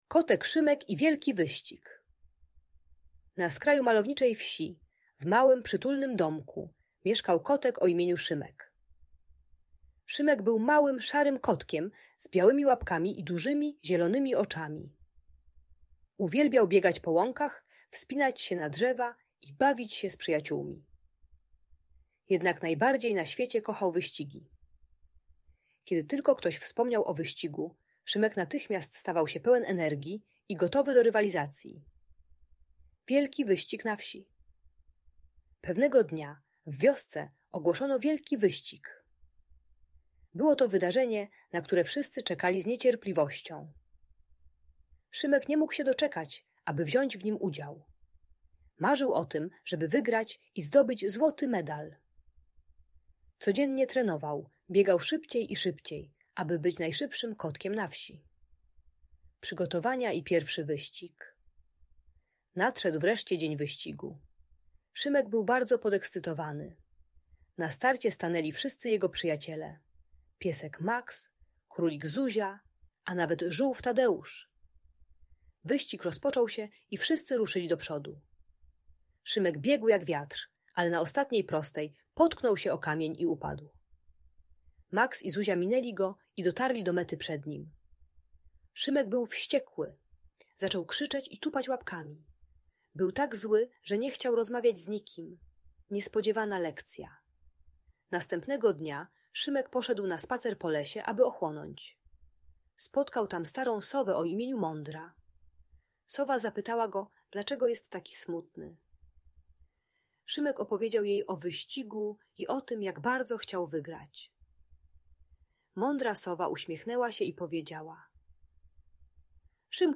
Kotek Szymek i Wielki Wyścig - Audiobajka dla dzieci